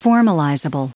Prononciation du mot formalizable en anglais (fichier audio)
Prononciation du mot : formalizable